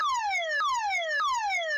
caralarm.wav